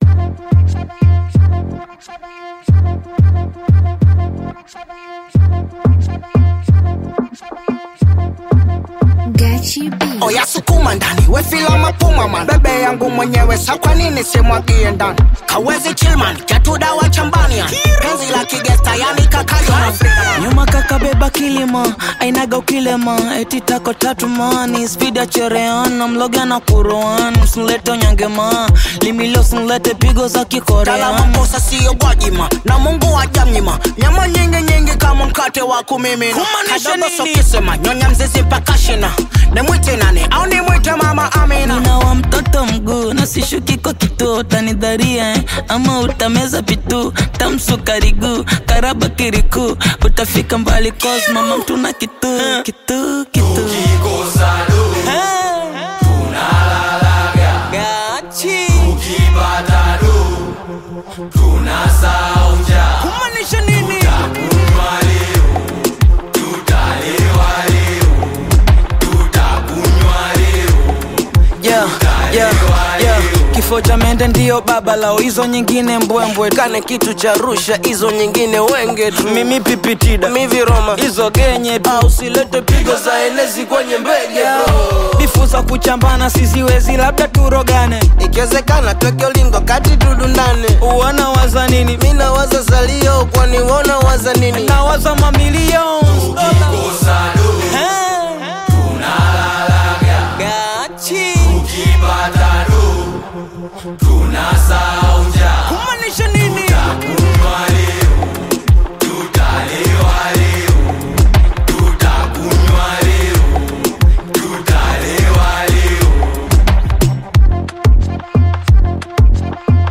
The energy on this track is absolutely insane.